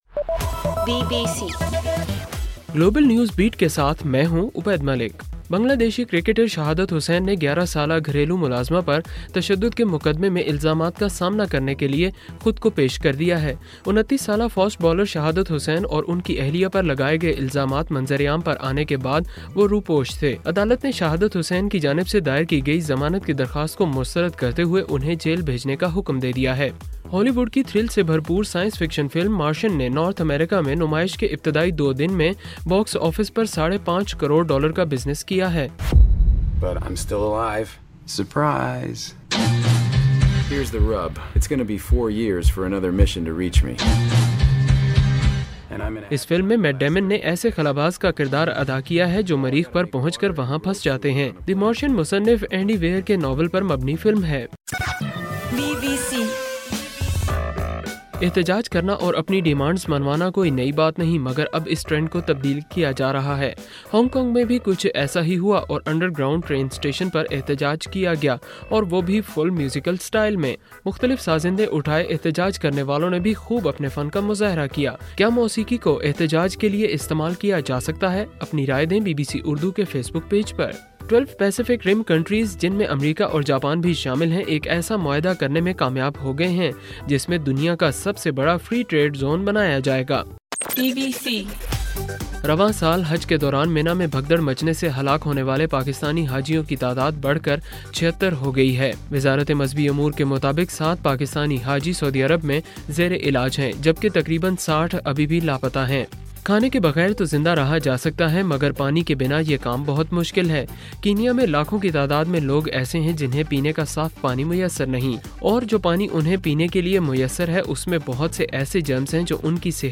اکتوبر 5: رات 10 بجے کا گلوبل نیوز بیٹ بُلیٹن